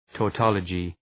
Προφορά
{tɔ:’tɒlədʒı}